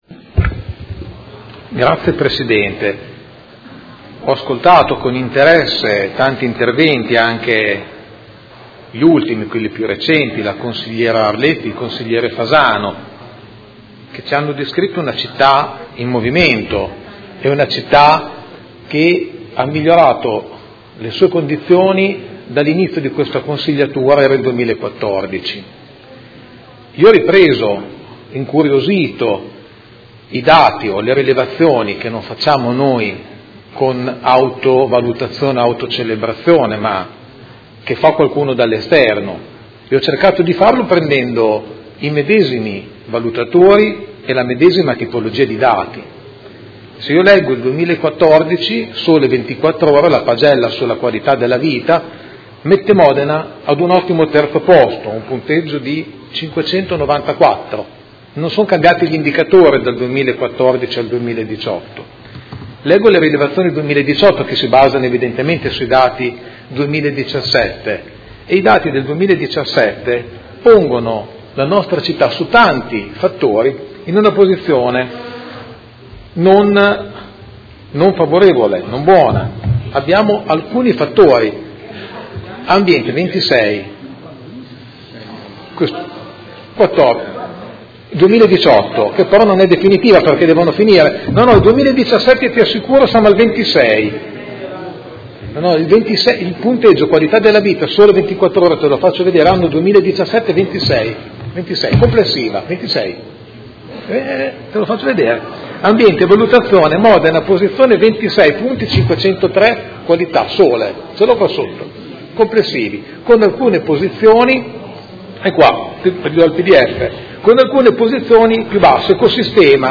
Dibattito su delibera di bilancio, Ordini del Giorno, Mozioni ed emendamenti